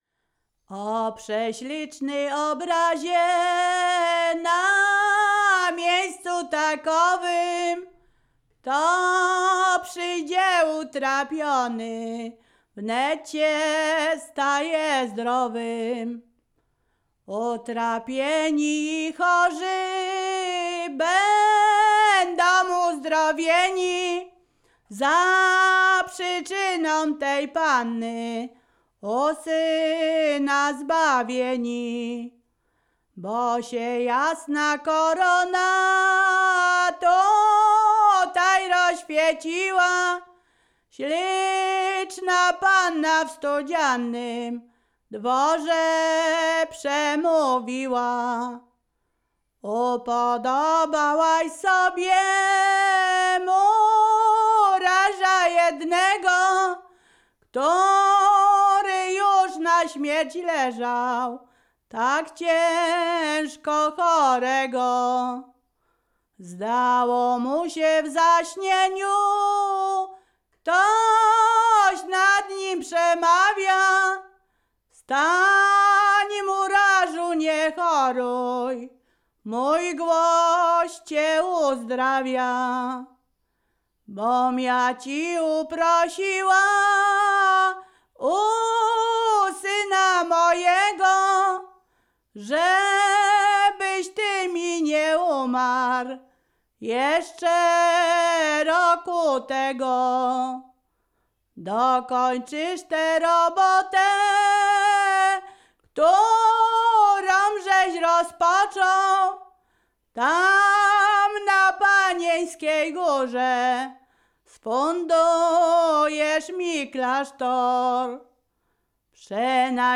Ziemia Radomska
ballady dziadowskie nabożne katolickie